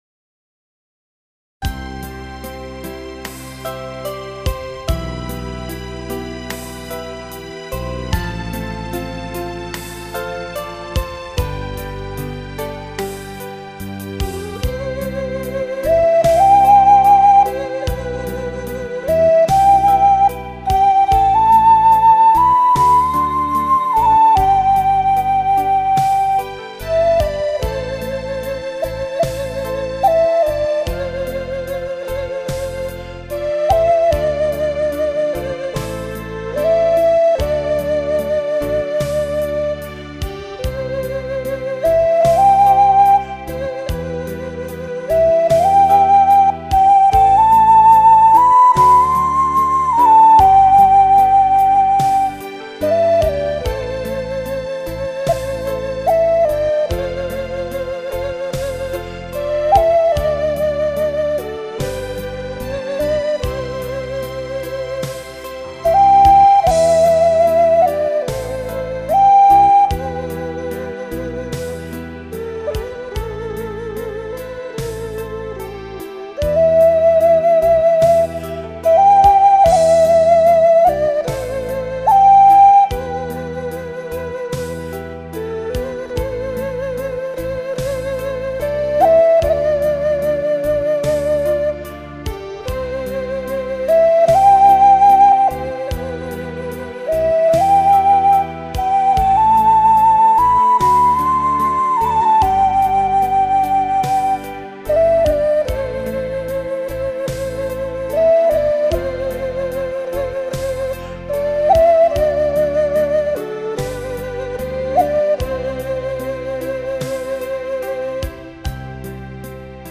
陶笛，属于管乐器的一员，笛大致上可分为直笛和球型笛两种，但发音原理皆是共通的。
不知怎样的，幽涉空灵的陶笛和流畅优美的葫芦丝扣人心弦，又隐含惆怅的演绎，如此地交融，灵气中隐隐流淌着那洗尽铅华的……
柔情似水的醉人旋律
[mjh5]B) 陶    笛[/mjh5]